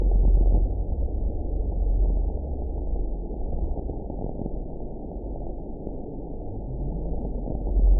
event 919967 date 01/31/24 time 10:44:45 GMT (1 year, 10 months ago) score 6.68 location TSS-AB08 detected by nrw target species NRW annotations +NRW Spectrogram: Frequency (kHz) vs. Time (s) audio not available .wav